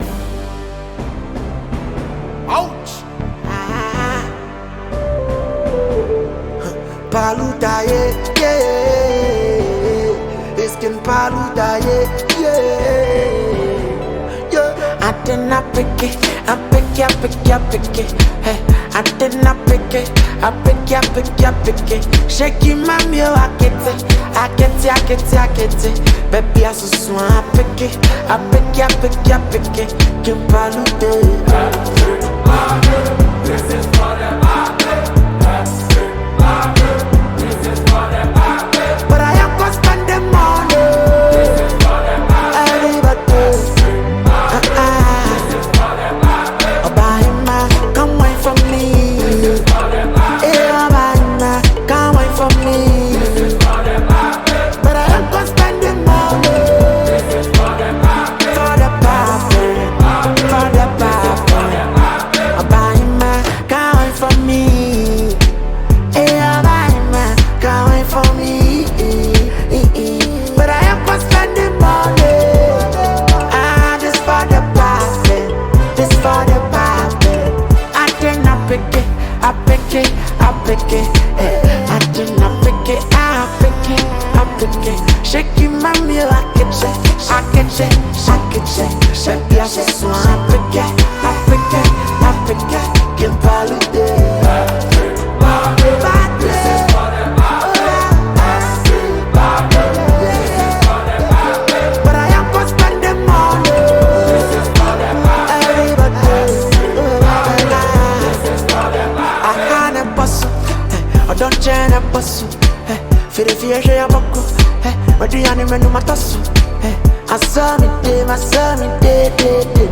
smooth vocals, the catchy hooks
blends Afrobeat with contemporary sounds
With its high energy tempo and catchy sounds